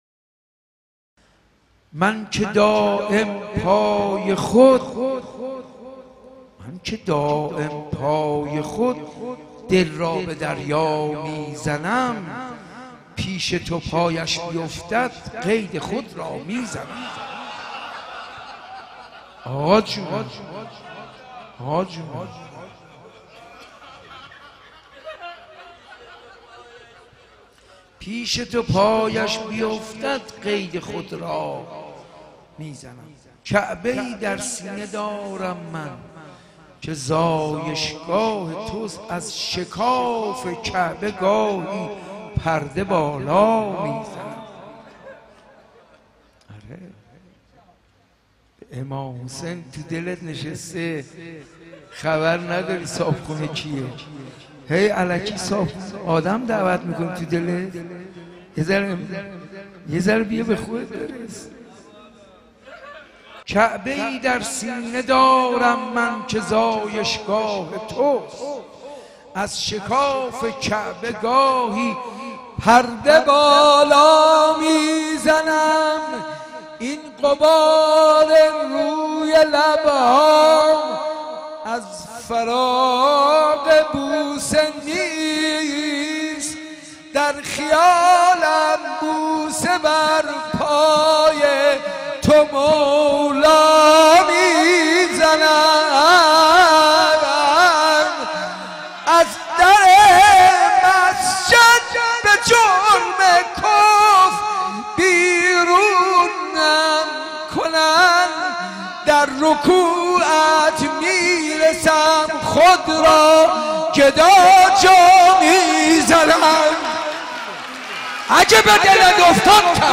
من که دائم پای خود دل را به دریا می‌ زنم | مدح
بیت الزهرا
حاج منصور ارضی
ولادت حضرت امیرالمومنین علی علیه السلام 1393